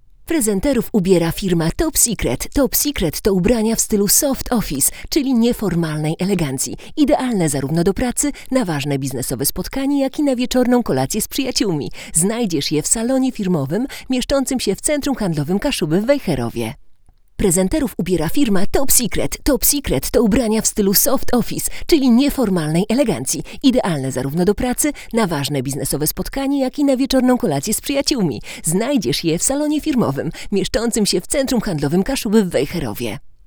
Ustawiamy klasyczną nerkę i jedziemy.
Nagranie wokal żeński
Brzmienie jest pełne, ale z odrobiną piasku. Klarowne, ale nie sterylne.